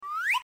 Converted sound effects